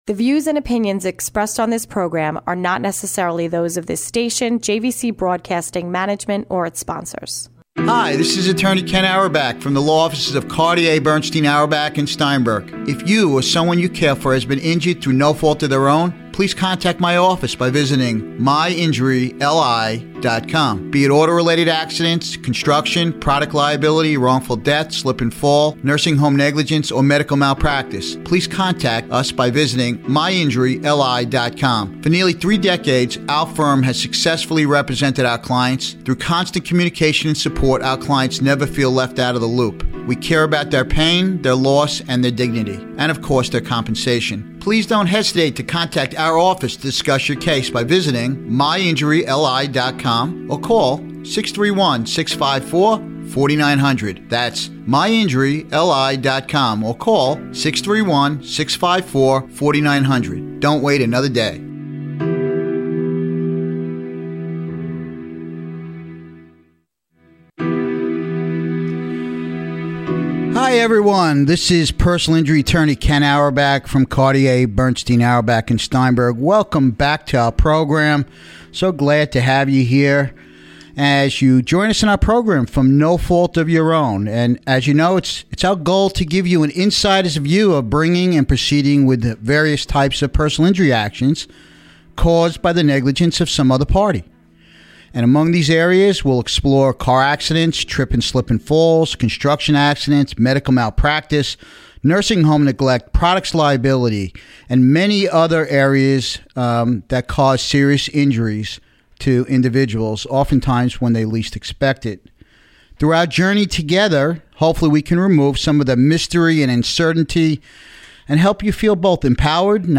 Car Accidents Insights: Mistakes Clients Make - Brain Injuries, with a Neurosurgeon as a guest on the show.